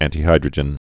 (ăntē-hīdrə-jən, ăntī-)